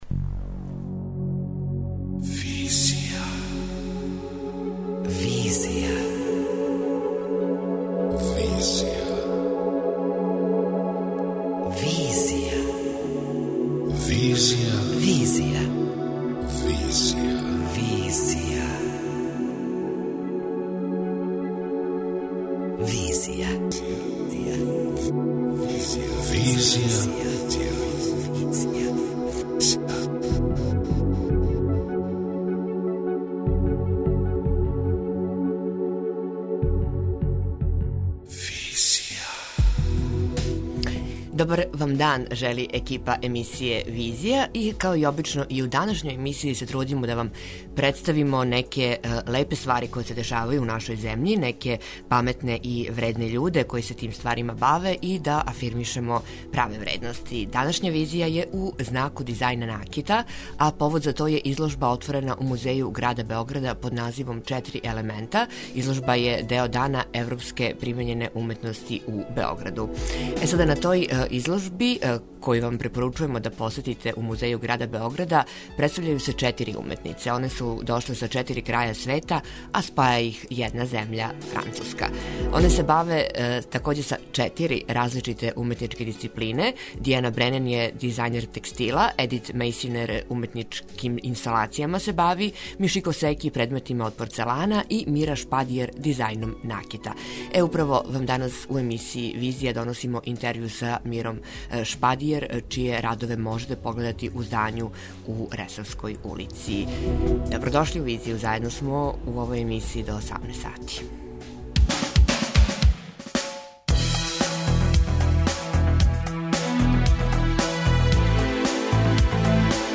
Интервју са једном од њих